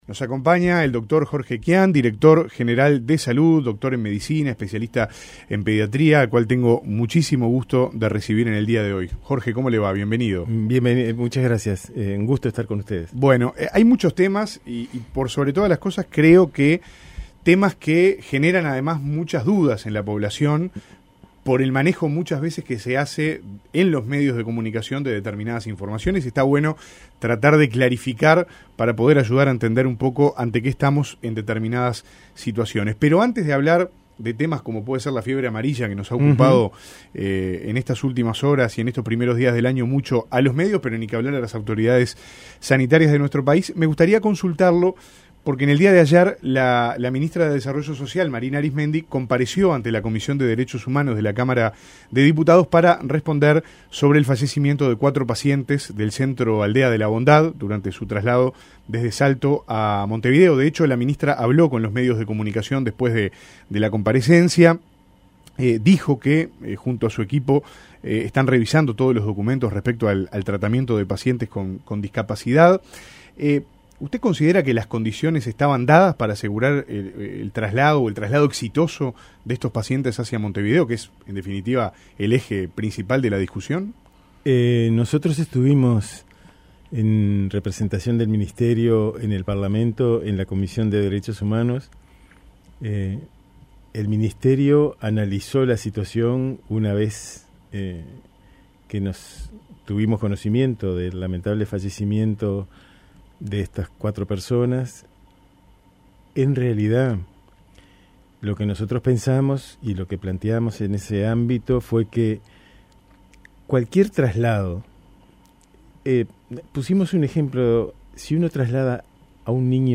Este jueves en La Mañana de El Espectador, estuvo Jorge Quian, director Nacional de Salud del Ministerio de Salud.